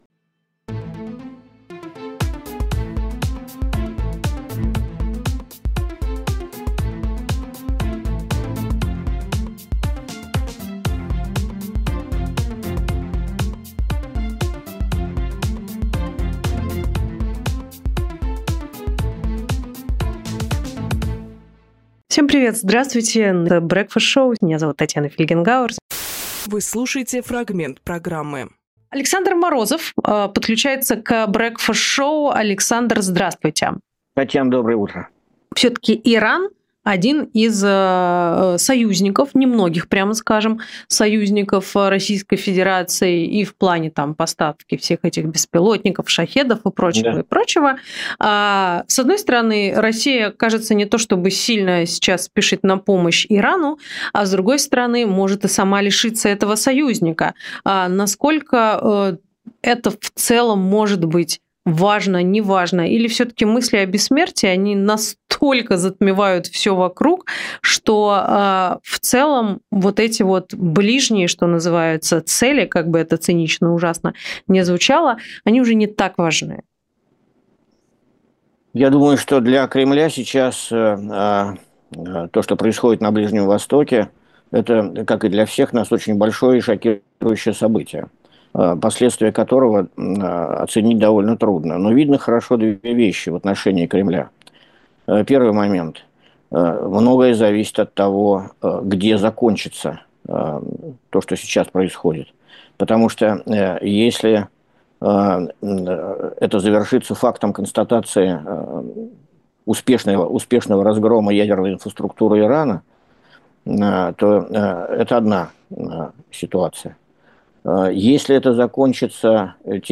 Фрагмент эфира от 16.06.25